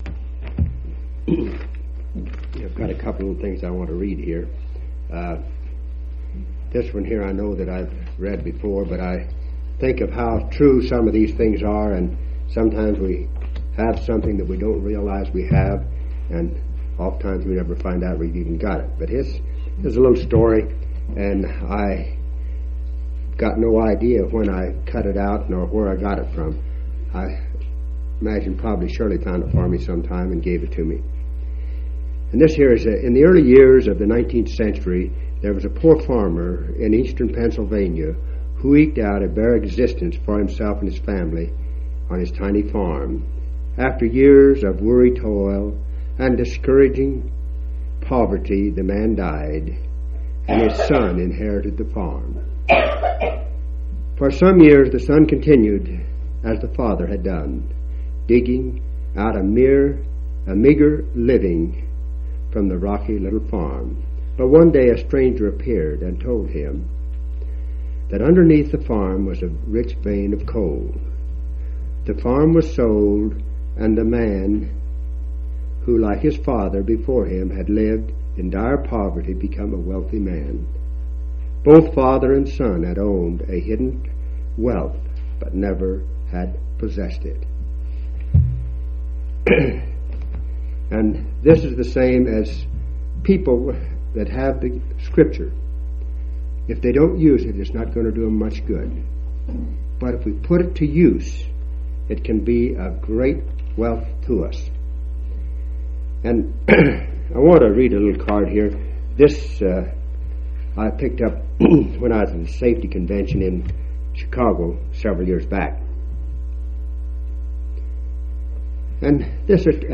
1/1/1985 Location: Grand Junction Local Event